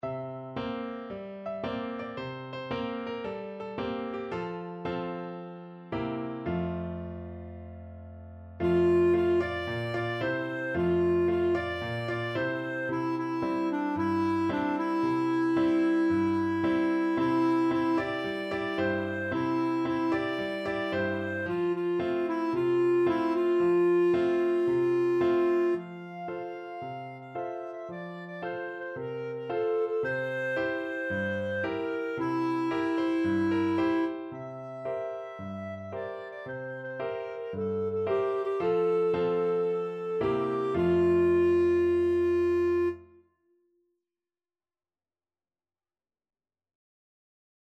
Clarinet
4/4 (View more 4/4 Music)
Cheerfully! =c.112
F major (Sounding Pitch) G major (Clarinet in Bb) (View more F major Music for Clarinet )
Traditional (View more Traditional Clarinet Music)